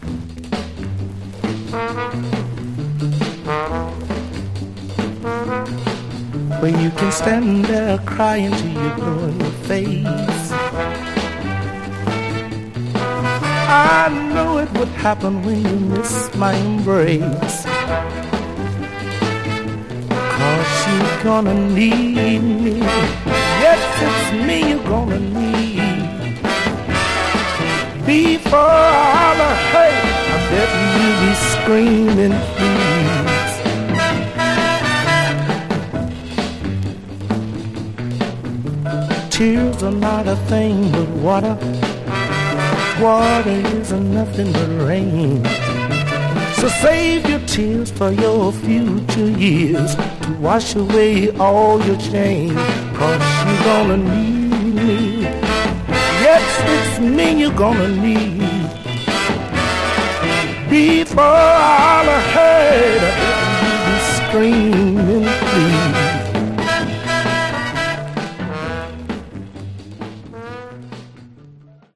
Category: RnB